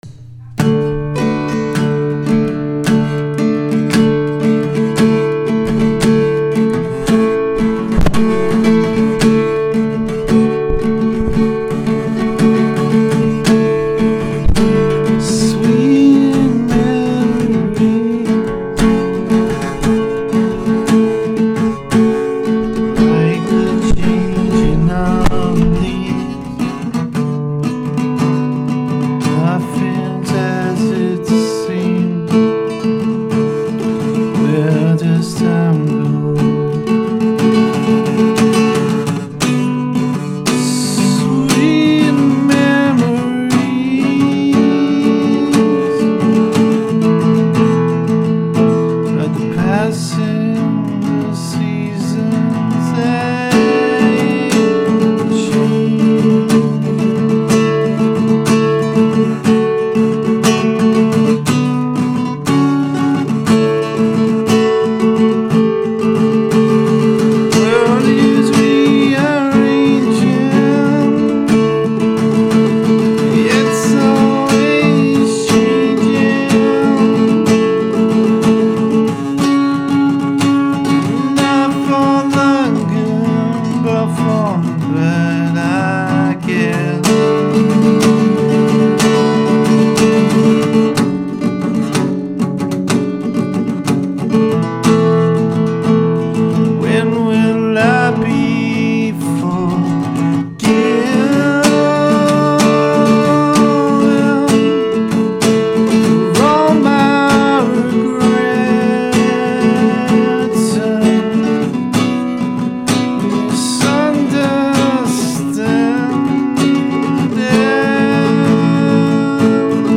just another sheep soong the mic pops a bit at the start sorry
vocal guitar